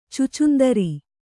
♪ cucundari